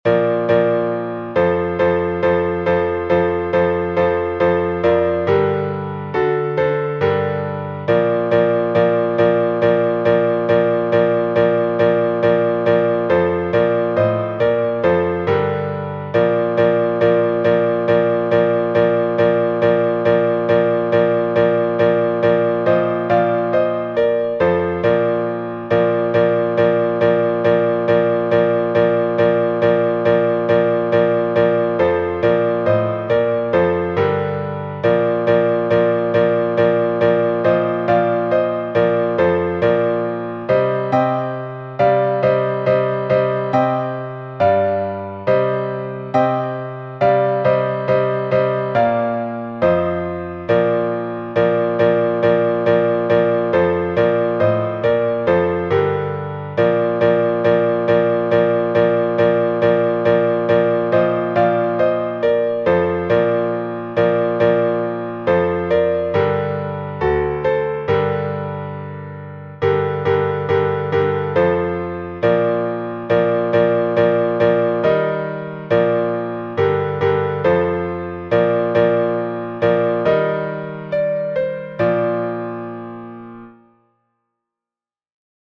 Малая ектения